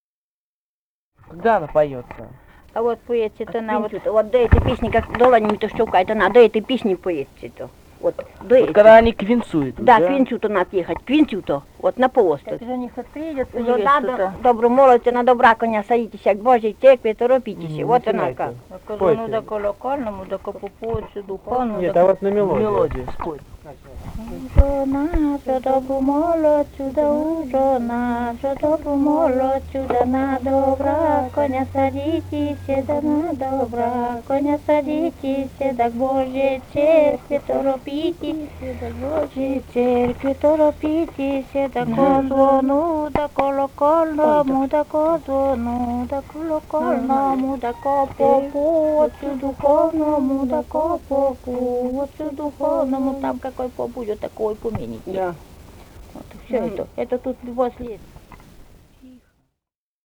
Этномузыкологические исследования и полевые материалы
«Ужо надо добру молодцу» (свадебная).
Вологодская область, д. Усть-Вотча Марьинского с/с Вожегодского района, 1969 г. И1132-31